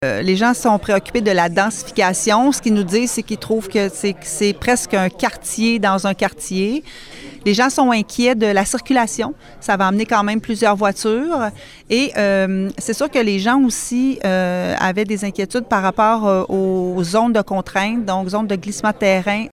En entrevue, la mairesse de Nicolet a résumé les principales inquiétudes des citoyens.